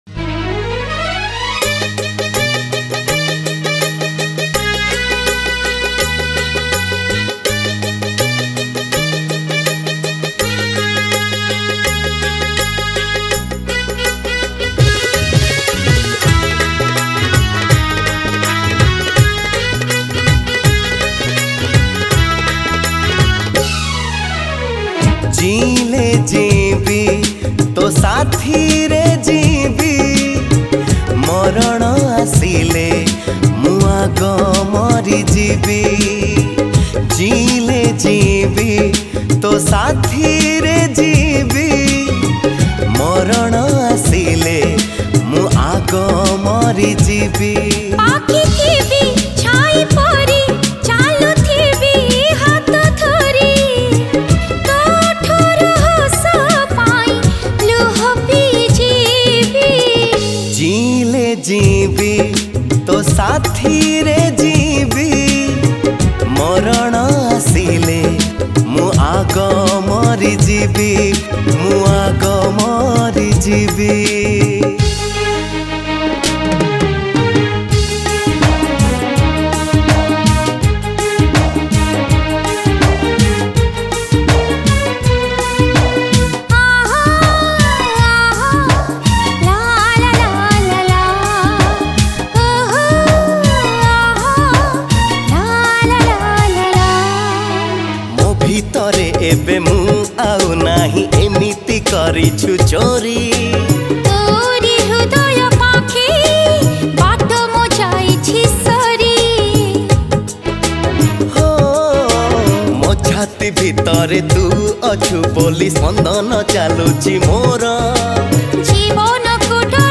Odia Jatra Song Songs Download